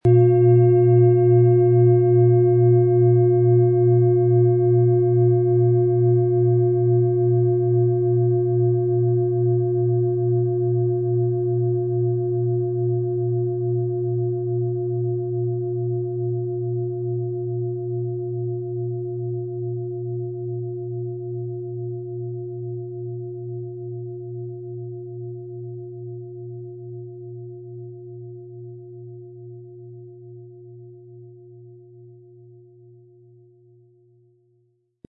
Spielen Sie die Schale mit dem kostenfrei beigelegten Klöppel sanft an und sie wird wohltuend erklingen.
PlanetentonMond & Platonisches Jahr (Höchster Ton)
MaterialBronze